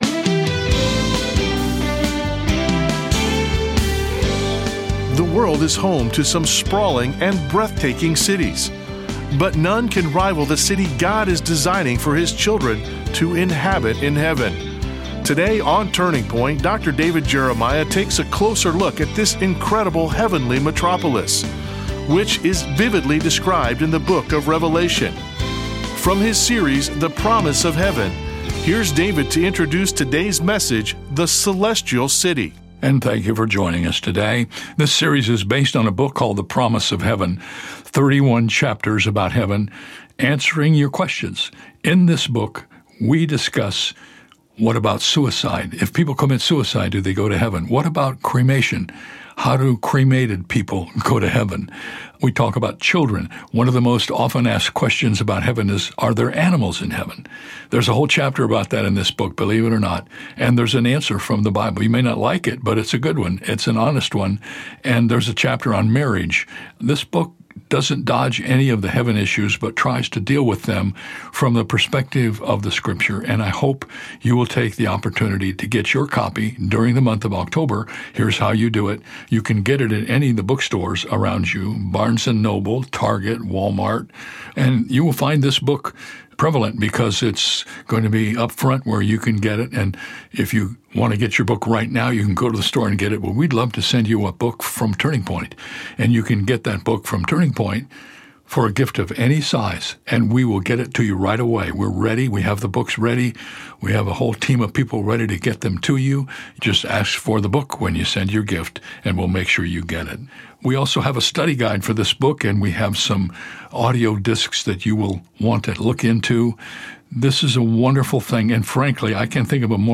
In this message, Dr. David Jeremiah offers a vivid look at the breathtaking beauty and design of the heavenly city described in Revelation.